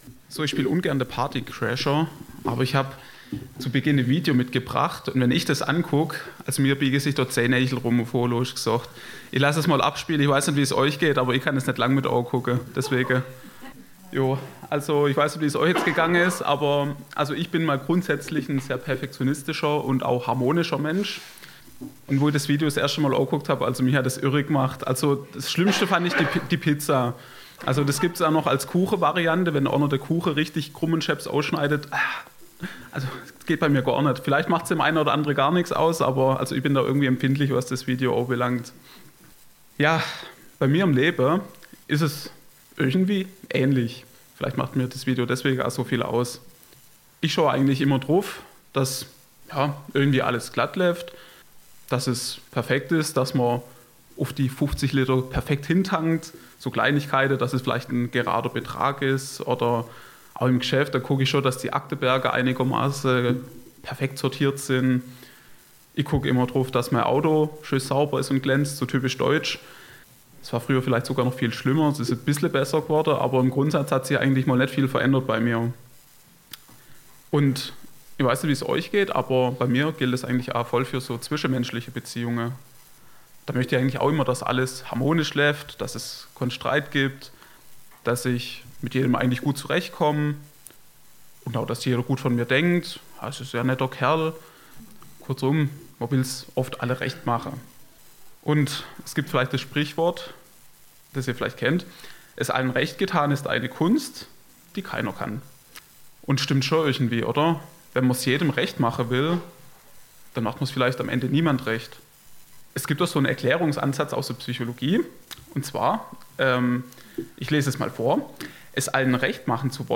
Predigt vom 12. Februar 2023 – Süddeutsche Gemeinschaft Künzelsau